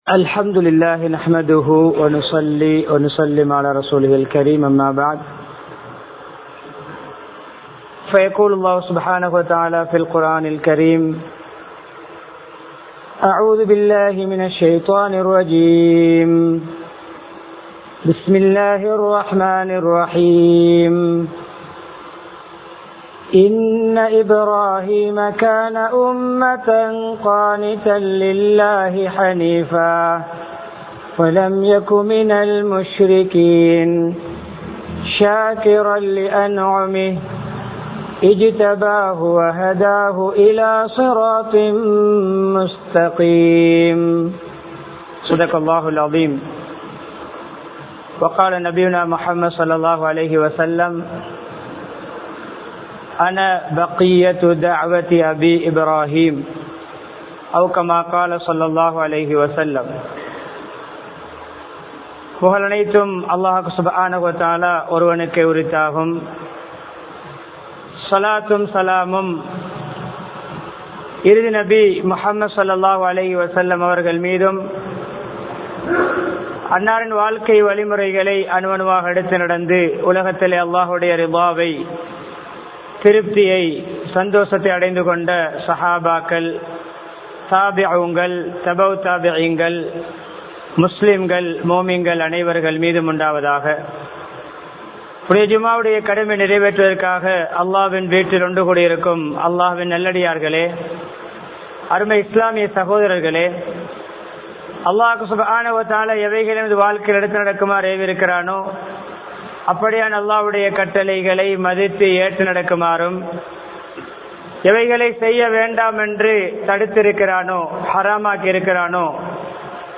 இப்றாஹிம்(அலை) அவர்களின் பண்புகள் | Audio Bayans | All Ceylon Muslim Youth Community | Addalaichenai
Kandy, Ilukkuwaththa Jumua Masjidh